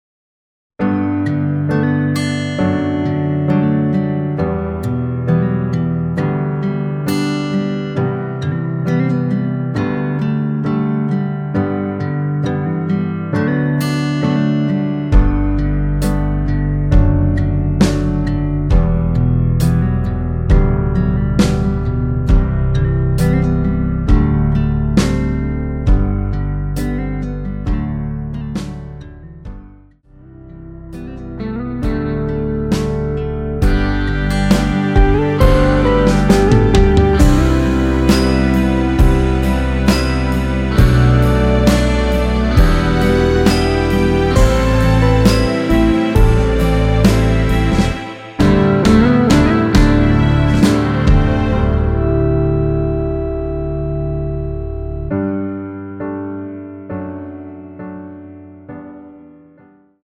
원키에서(-1)내린 1절후 후렴(2절삭제)으로 진행되는 MR 입니다.(미리듣기및 가사 참조)
◈ 곡명 옆 (-1)은 반음 내림, (+1)은 반음 올림 입니다.
앞부분30초, 뒷부분30초씩 편집해서 올려 드리고 있습니다.